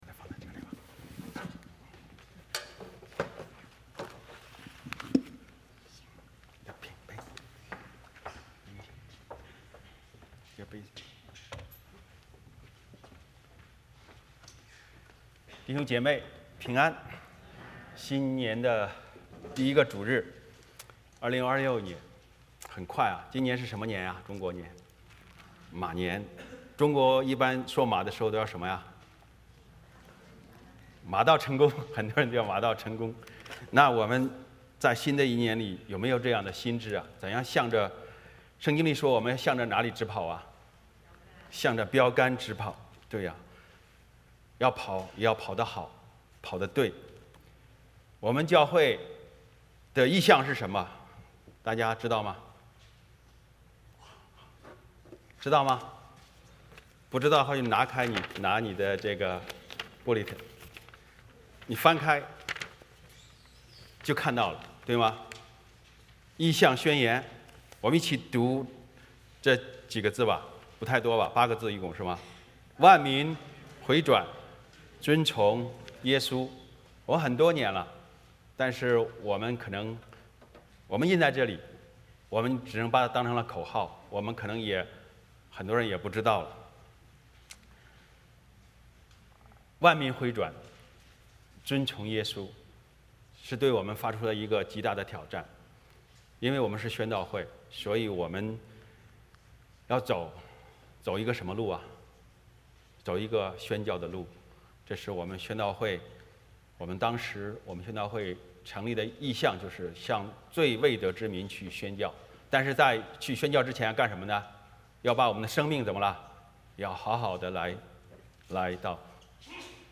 欢迎大家加入我们国语主日崇拜。
1-26 Service Type: 圣餐主日崇拜 欢迎大家加入我们国语主日崇拜。